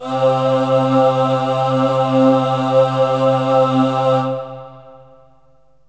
VOX_CHORAL_0002.wav